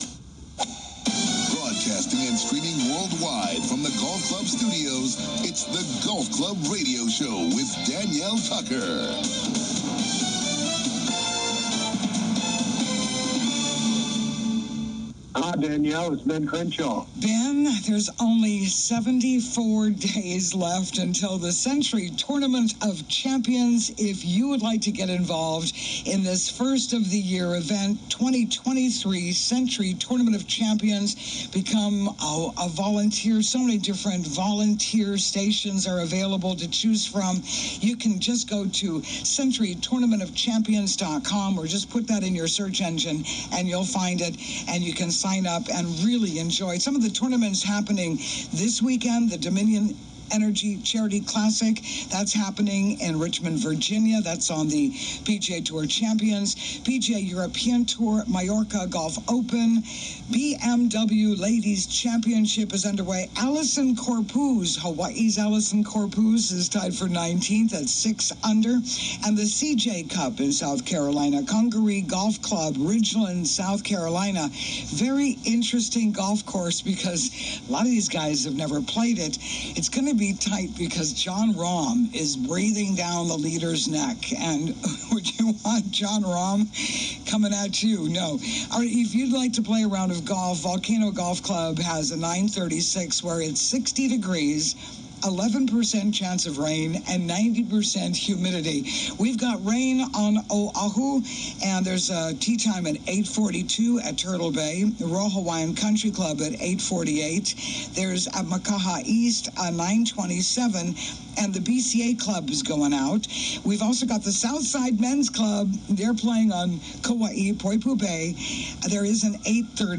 COMING TO YOU LIVE FROM THE GOLF CLUB STUDIOS ON LOVELY OAHU�s SOUTH SHORE � WELCOME INTO THE GOLF CLUB HOUSE!